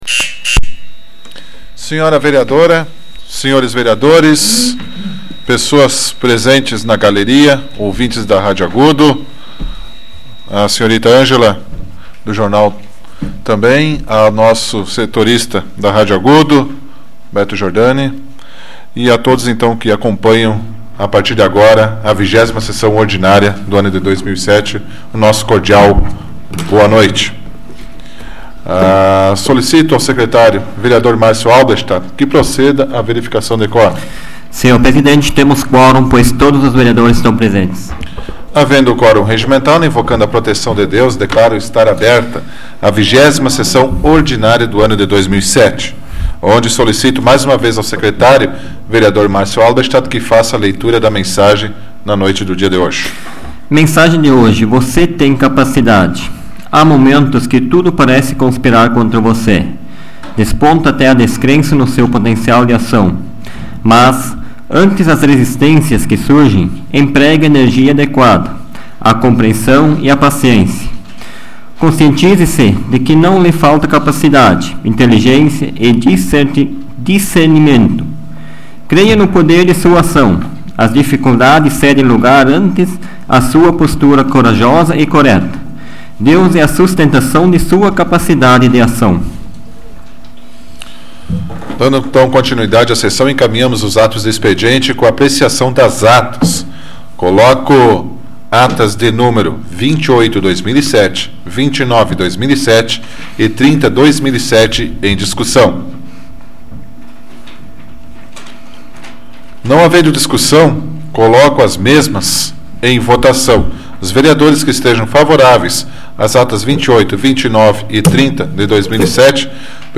Áudio da 94ª Sessão Plenária Ordinária da 12ª Legislatura, de 20 de agosto de 2007